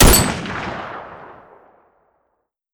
PNRoyalRifleSound.back